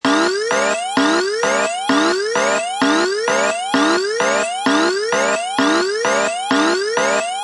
siren-alert-96052.mp3